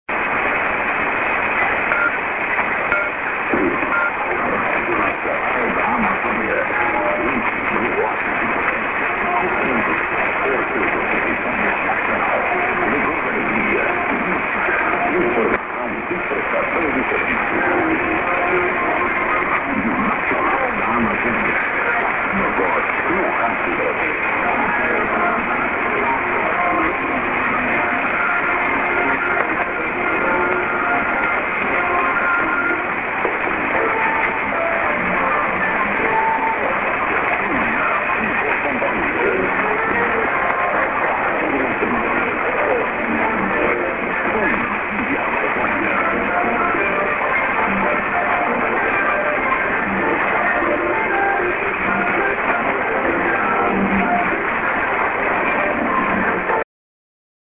->ID(men)->music